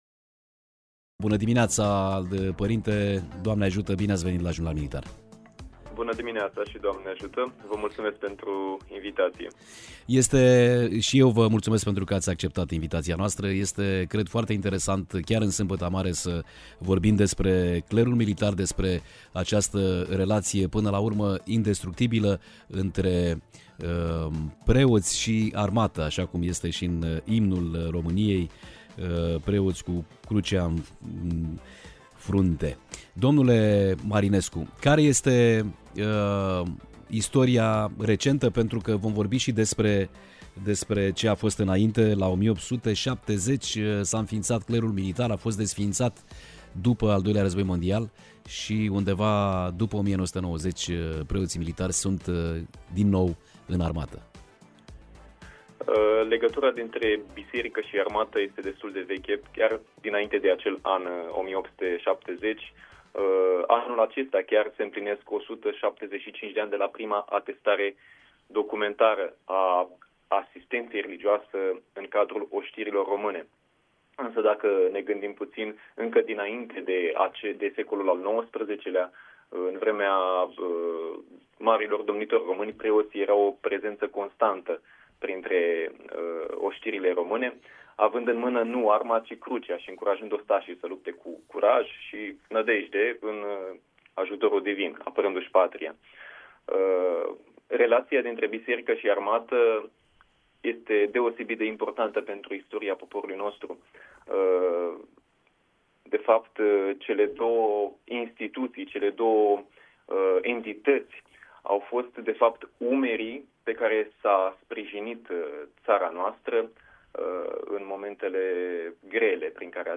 interviul-saptamanii-jurnal-militar-19-apr-25.mp3